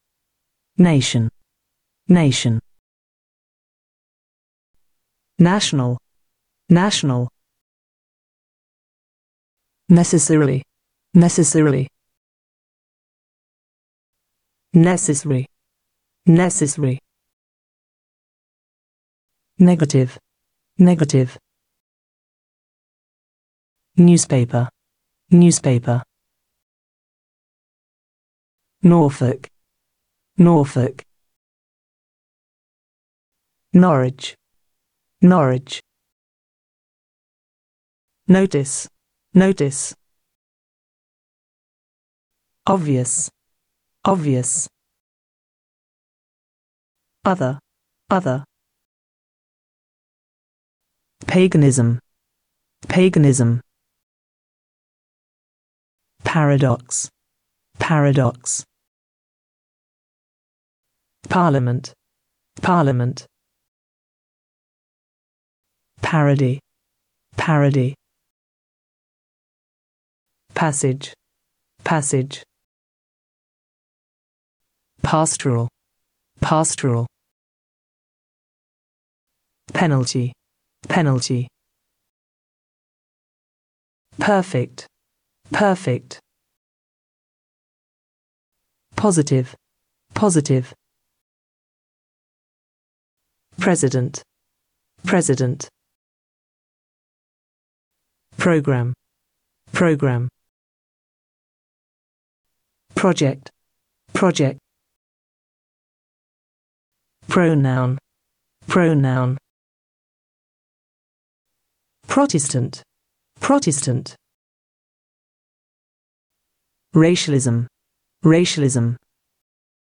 Fichiers MP3 English (UK) Rachel Speed -2 sur Natural Readers
Chaque mot est prononcé 2 fois : à vous de répéter après chaque modèle !